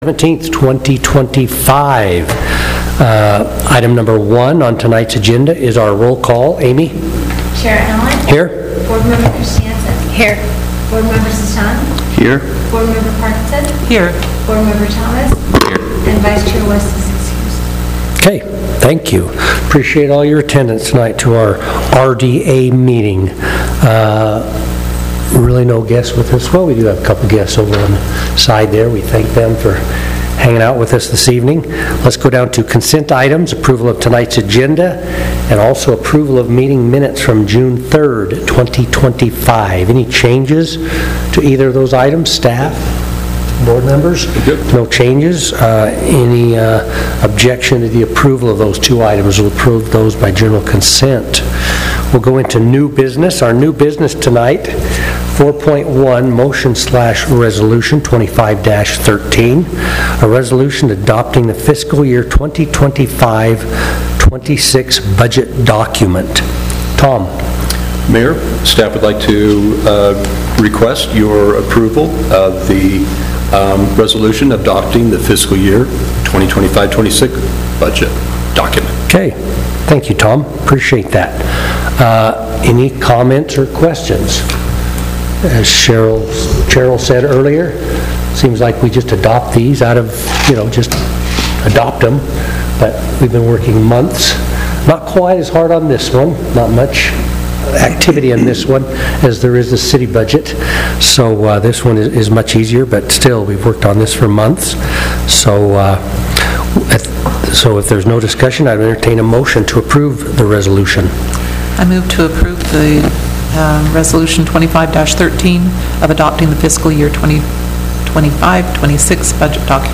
Notice, Meeting
Washington Terrace, UT 84405